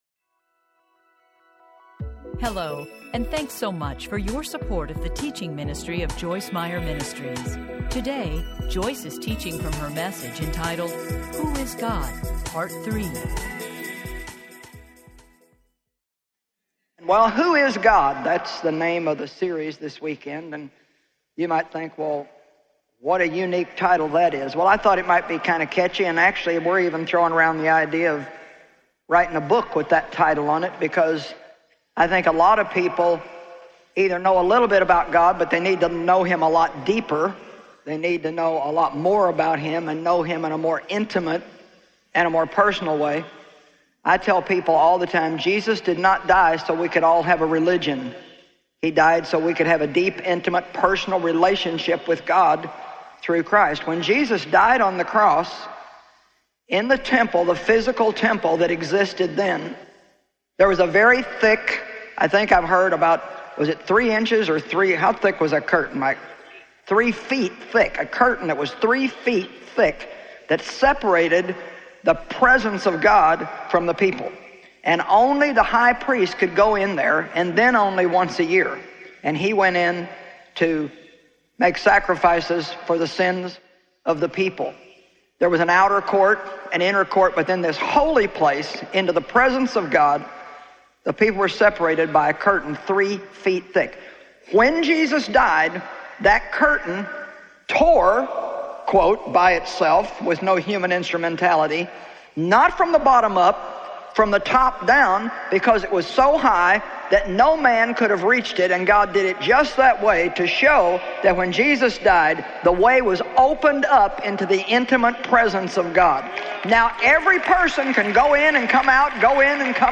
Who Is God? Teaching Series Audiobook
Narrator